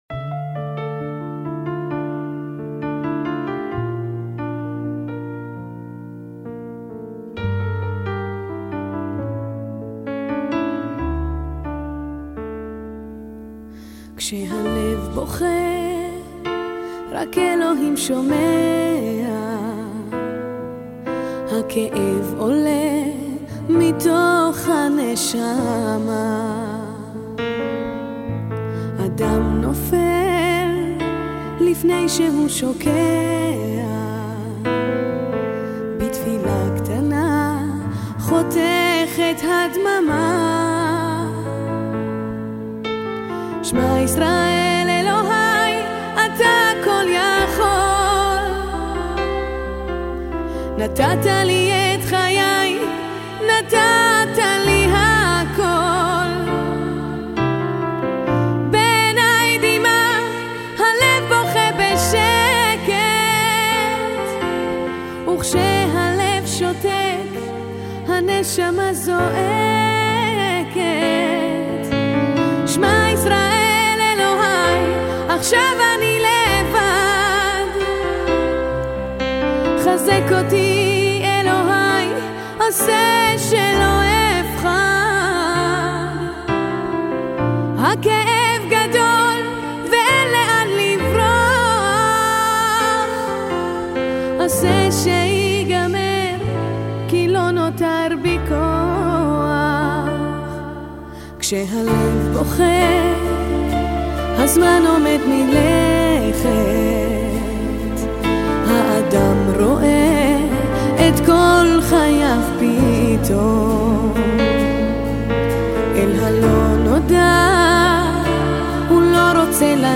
Еврейская музыка (закрыта)